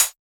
RDM_TapeB_SY1-HfHat.wav